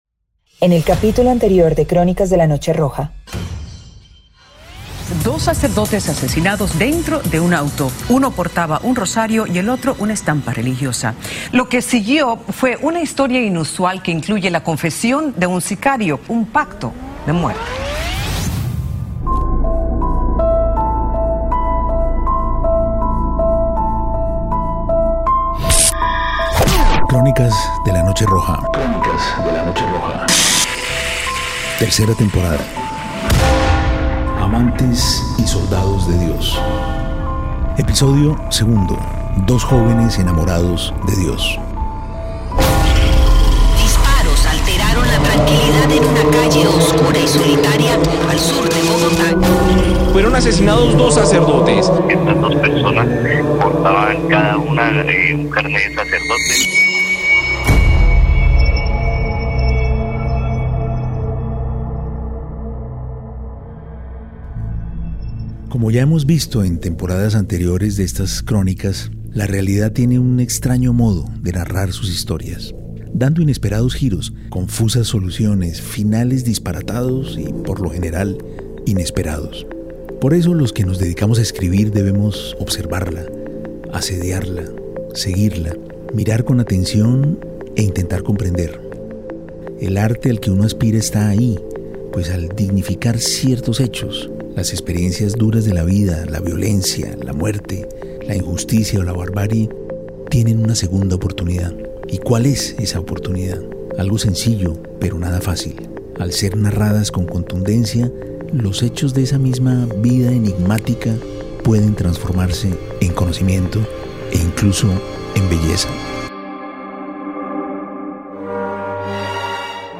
Los sacerdotes encaminaron su vida al servicio de Dios y del pueblo, pero ¿quiénes eran realmente?, ¿cómo describir a estos personajes?, ¿por qué llegaron hasta ese enigmático y triste final? Familiares, feligreses y amigos dan su testimonio.